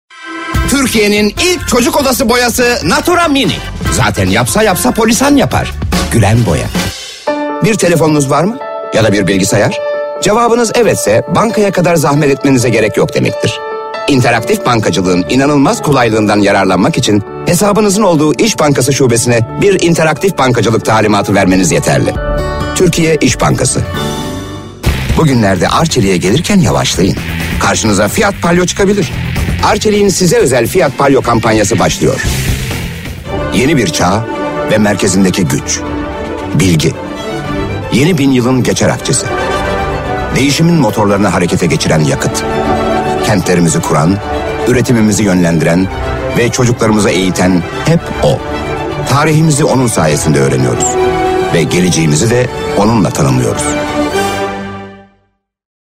Reklam Demo